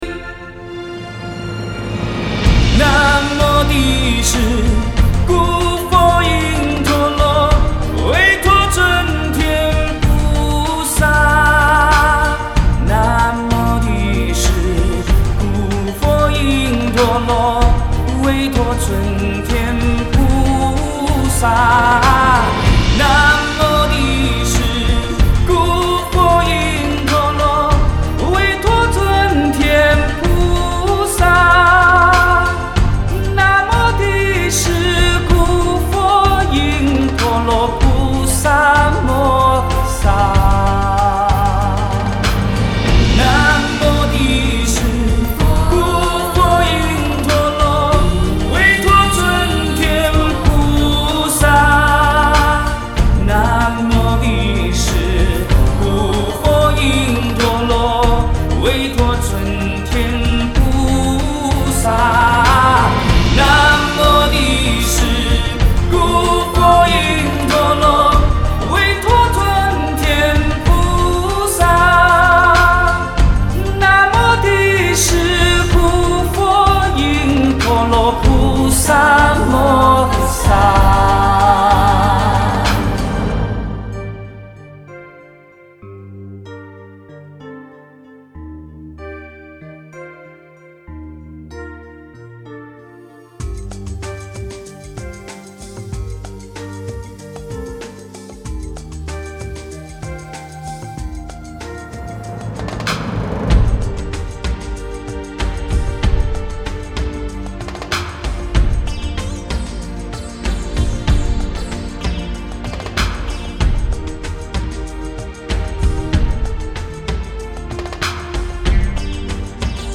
庄严的激昂的